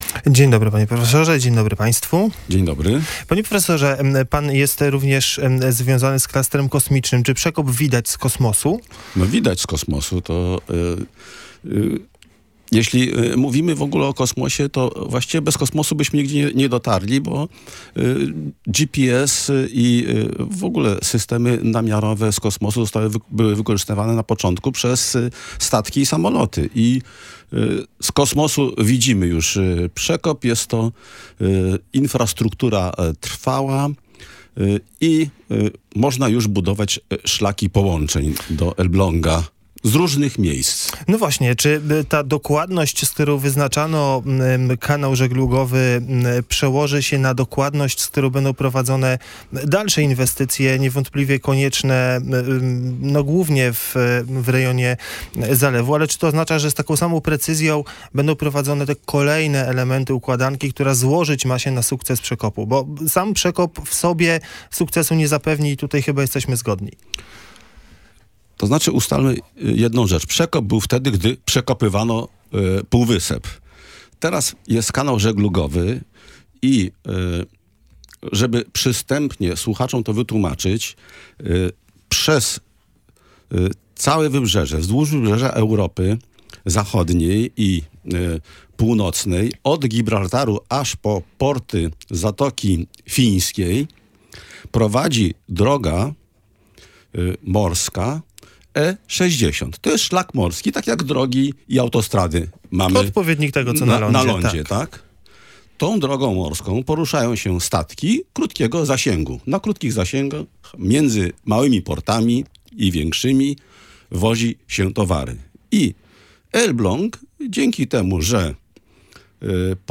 Elbląg, dzięki temu że powstał kanał żeglugowy, dostał odcinek drogi, przy którym można rozwijać inwestycje – wyjaśnił „Gość Dnia Radia Gdańsk”.